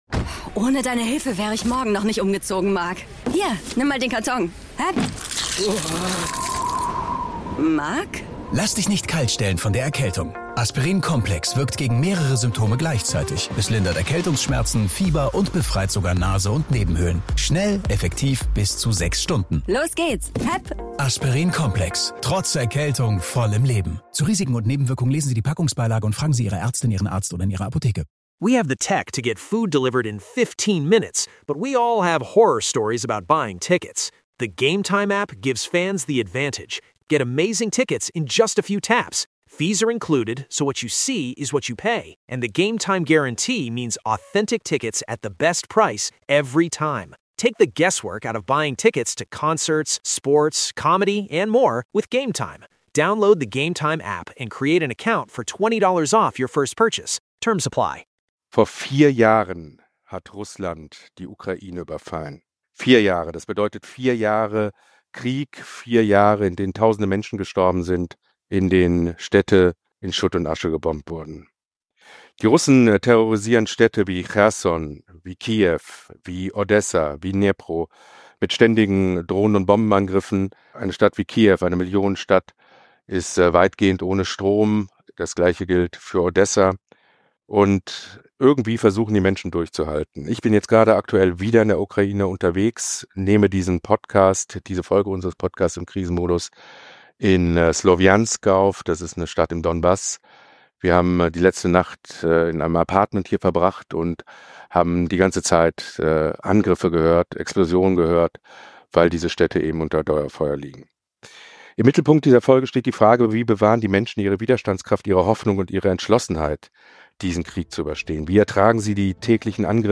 mit Menschen gesprochen, die seit vier Jahren mit den furchtbaren Folgen des russischen Krieges leben.